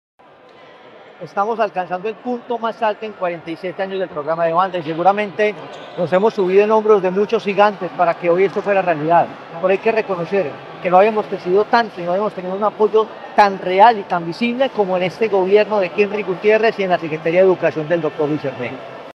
Diputado Hernán Alberto Bedoya.